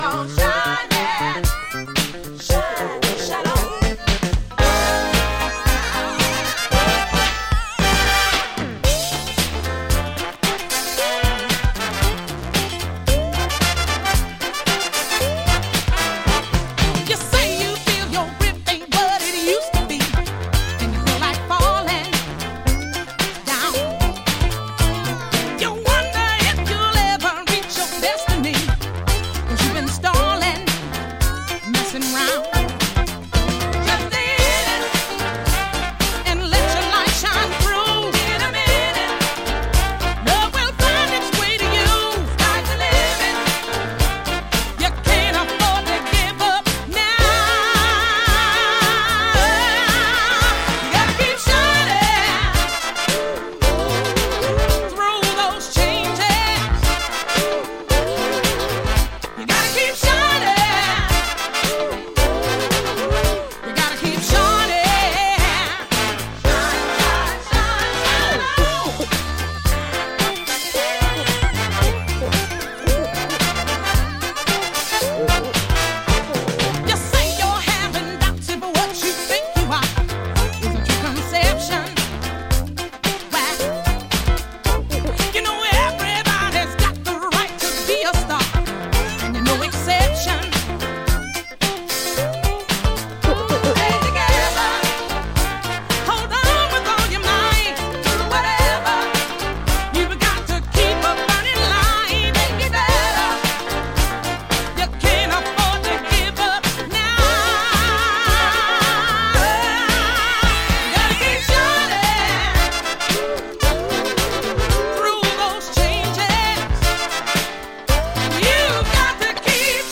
ファンキーなブギー・チューンのA面、そして絶品のミディアム・スローのB面共に最高！